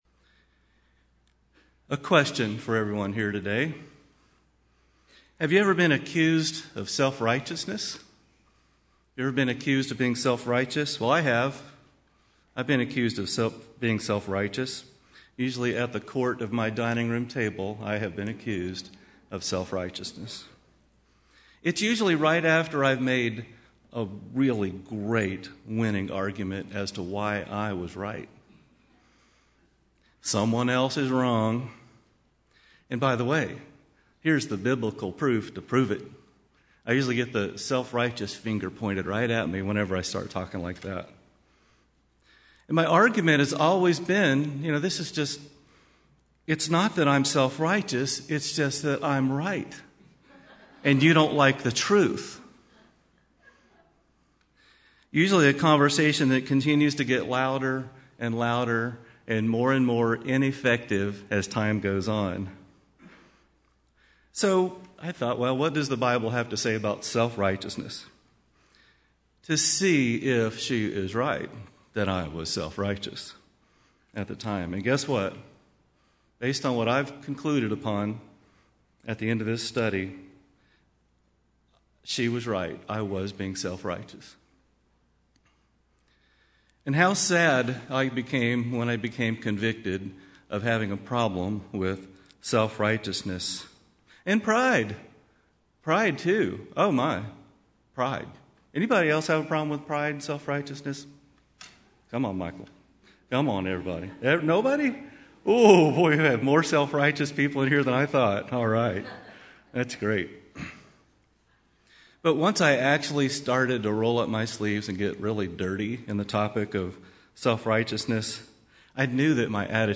Sermons
Given in Nashville, TN